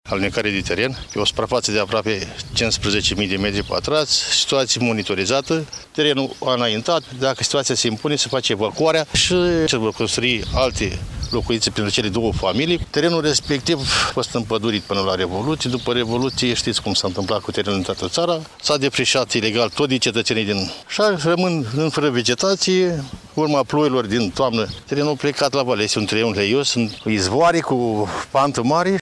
Primarul comunei Voinesti, Aurelian Miller, a spus că alunecările de teren au fost antrenate de ploile din toamna trecută și de ninsorile din luna aprilie pe fondul defrişărilor masive din zonă: